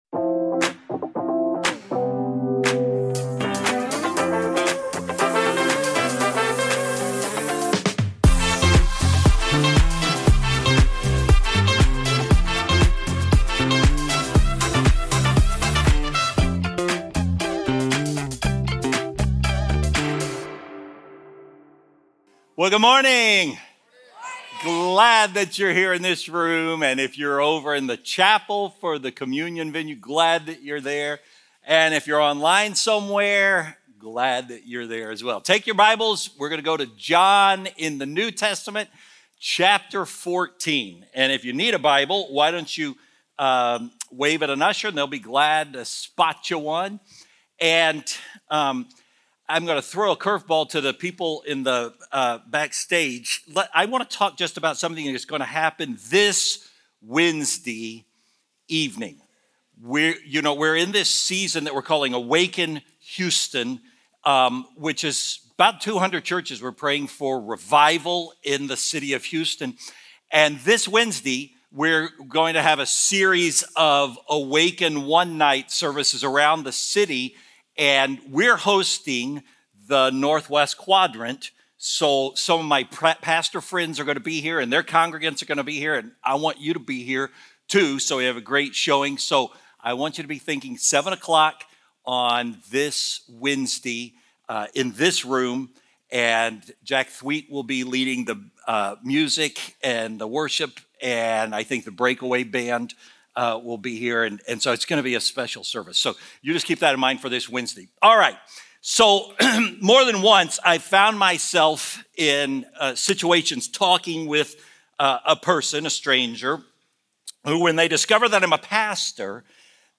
Weekly biblically-based sermons from Faithbridge church in Spring, Texas.